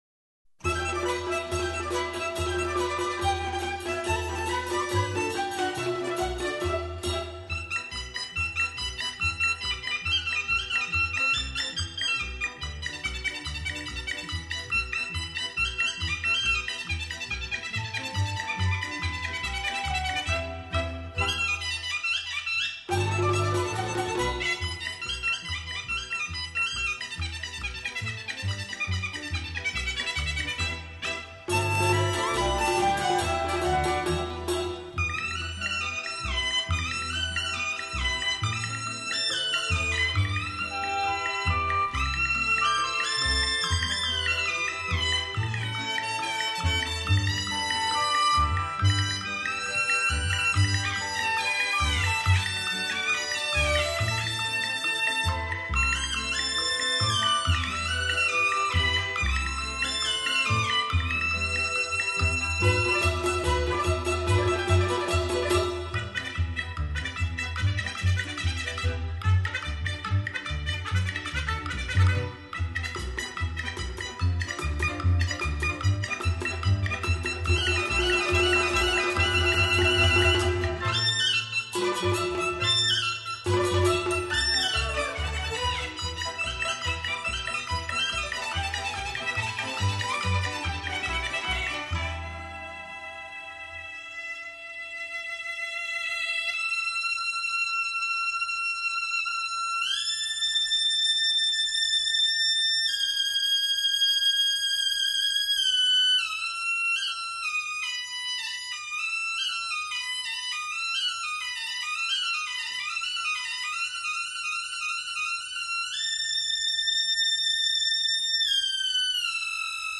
其演奏干净利落，从容自如，音色华美。